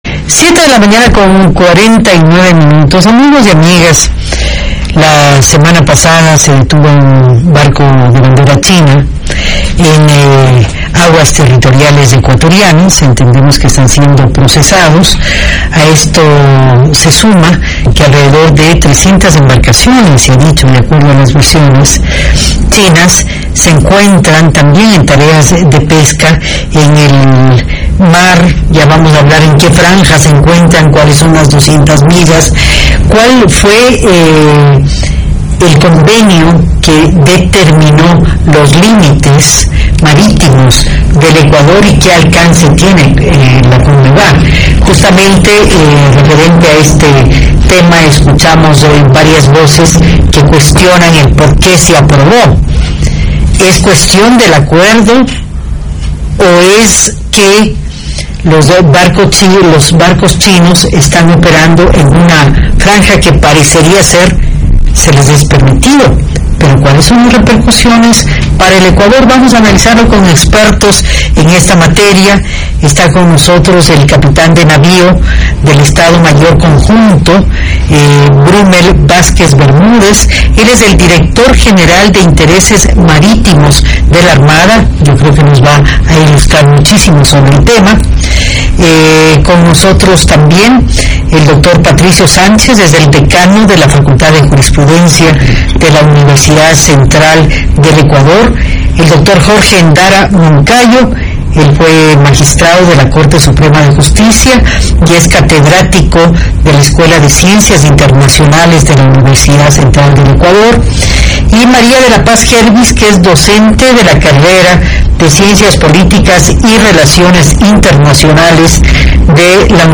dialogaron sobre el alcance del tratado de Conmevar.